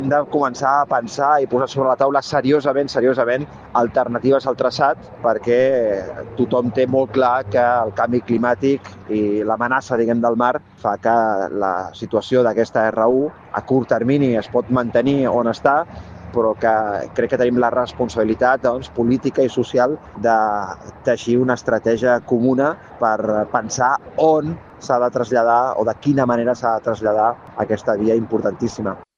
S’impulsarà una declaració conjunta amb el Consell Comarcal del Maresme per instar a les administracions superiors a repensar-ne el traçat. El responsable de Mobilitat i alcalde de Premià de Mar, Rafael Navarro, ha dit a l’emissora municipal que l’amenaça del canvi climàtic obliga a valorar seriosament les alternatives possibles.